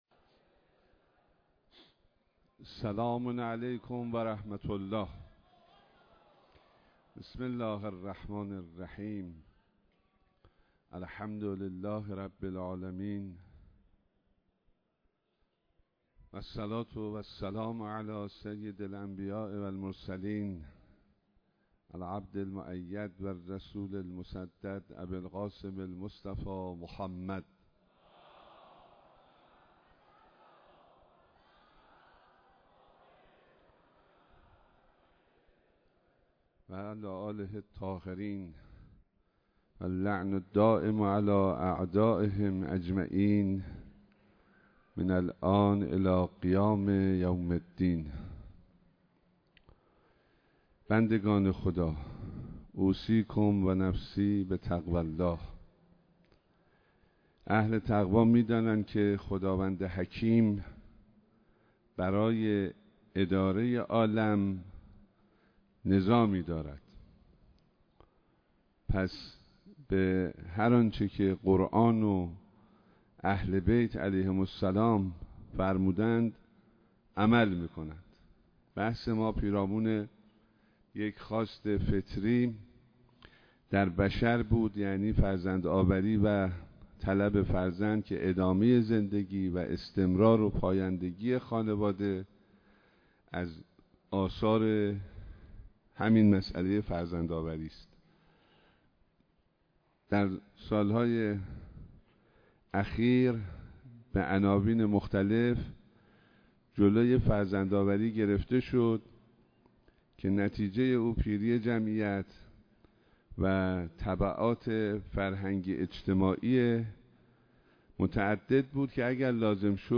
ایراد خطبه‌های نماز جمعه شهرستان کرج به امامت آیت‌الله حسینی همدانی نماینده ولی‌فقیه در استان البرز و امام‌جمعه کرج
صوت خطبه‌های نماز جمعه پنجم خردادماه شهرستان کرج
به گزارش روابط عمومی دفتر نماینده ولی‌فقیه در استان البرز و امام‌جمعه کرج، نماز جمعه پنجم خردادماه هزار و چهارصد و دو شهرستان کرج به امامت آیت‌الله حسینی همدانی در مصلای بزرگ امام خمینی (ره) برگزار شد.